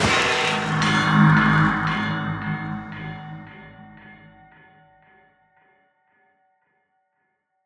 Trident thunder 1.ogg